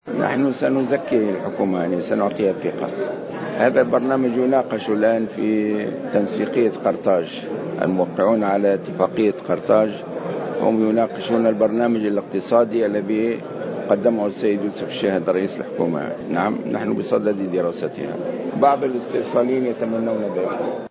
وأضاف الغنوشي في تصريح لمراسل الجوهرة اف ام على هامش افتتاح المؤتمر الوطني الثالث لحزب التكتل، أن الأطراف الموقعة على وثيقة قرطاج بصدد دراسة ومناقشة البرنامج الاقتصادي لحكومة "الشاهد 2" الذي قدمه رئيس الحكومة.